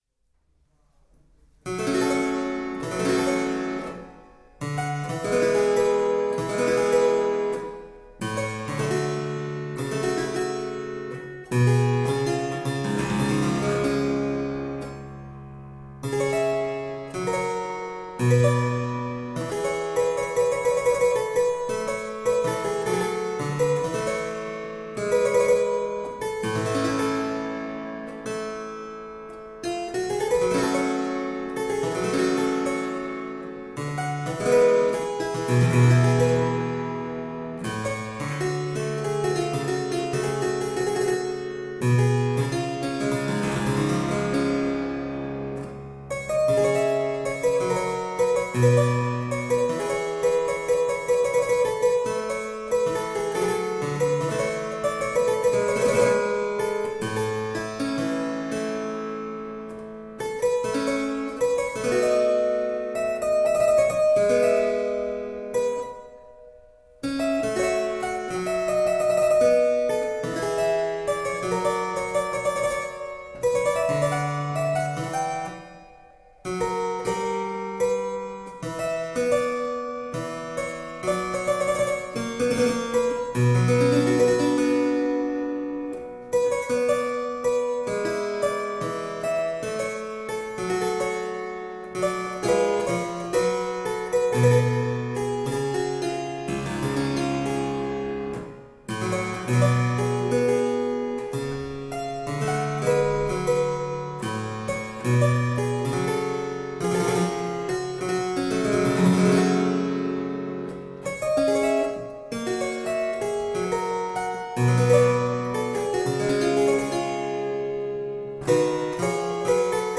Registro: uno da 8'
Estensione: Sol0 - sol5
Trasposizione: 415 - 440
Spinetta inglese
Costruito integralmente seguendo il modello originale, possiede un'ampia estensione di tastiera, la doppia curva della cassa e una incordatura interamente in ottone, il tutto per rendere un suono dolce e brillante al contempo, per cui, oltreché utile per lo studio personale, risulta assai gradito nell'accompagnamento di piccoli ensemble.